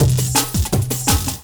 35 LOOP01 -R.wav